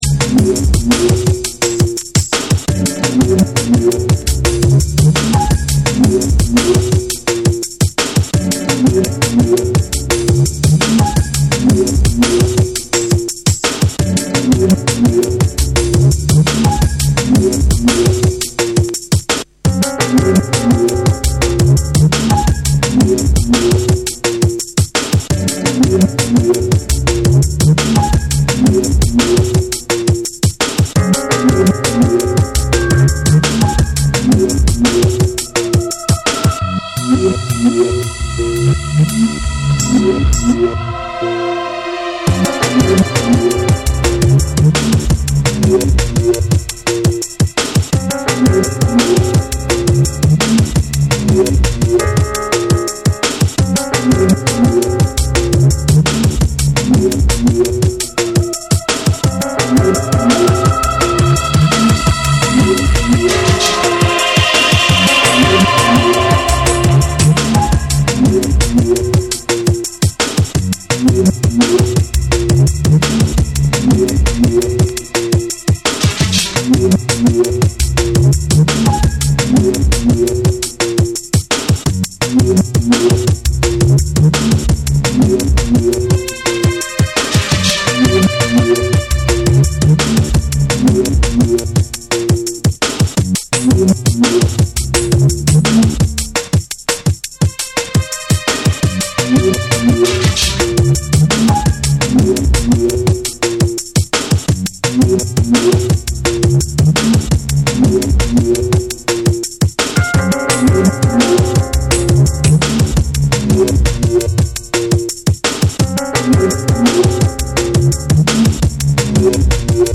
タイトに刻まれるブレイクと重量感あるベースラインが絡み合う
抜けのあるタイトなビートにミステリアスなピアノ・フレーズが重なり、緊張感を孕んだ展開をみせる
JUNGLE & DRUM'N BASS